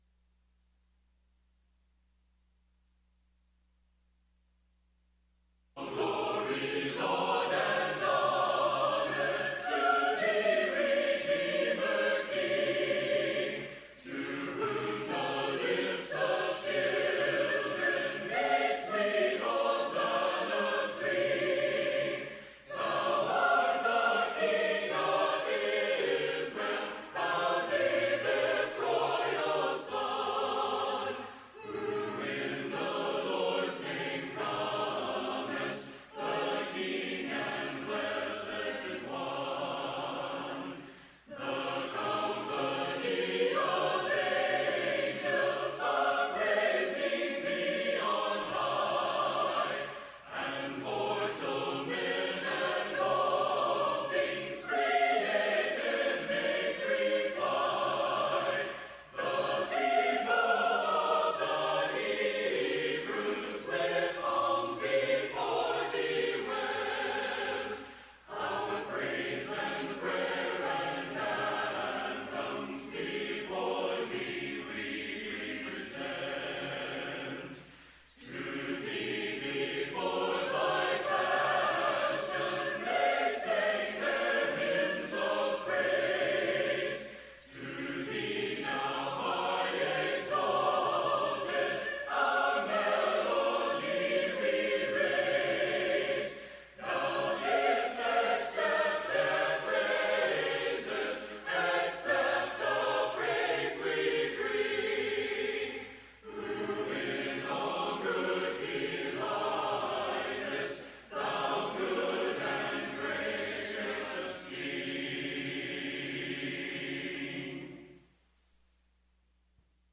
Proverbs 28:14, English Standard Version Series: Sunday PM Service